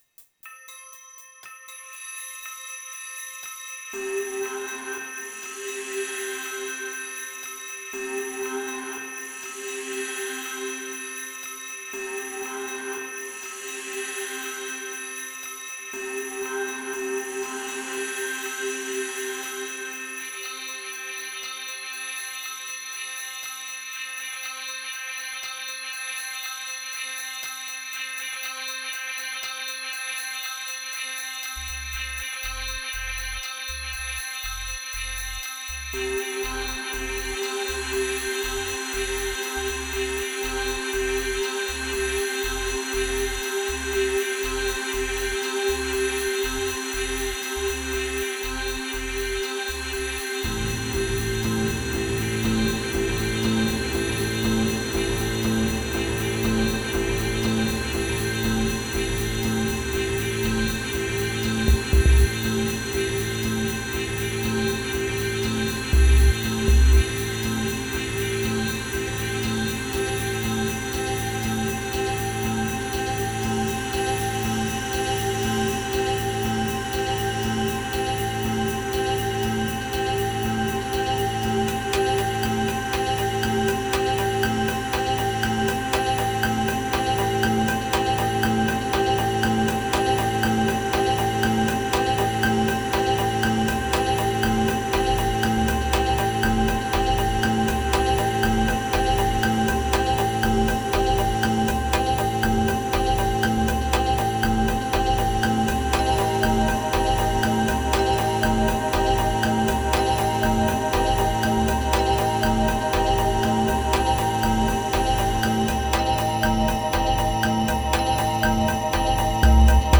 Kicks Median Chill Energy Hypnotic Decay Morn